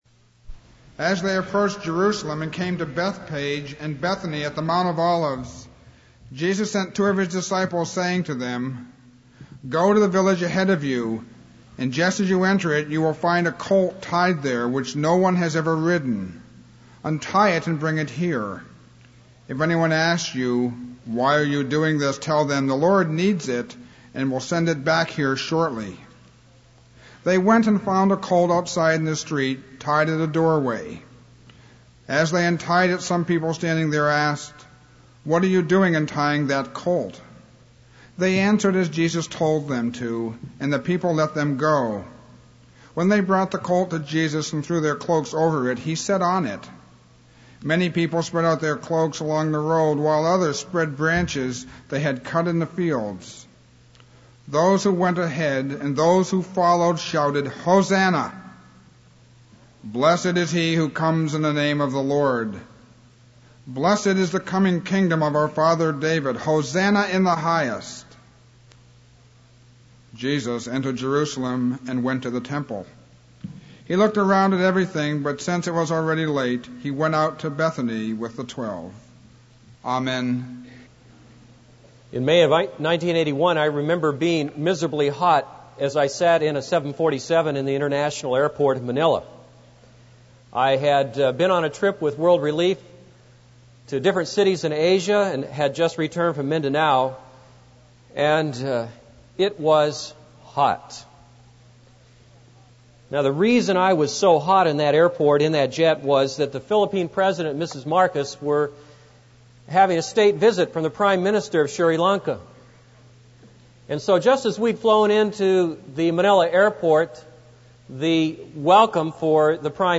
This is a sermon on Mark 11:1-11.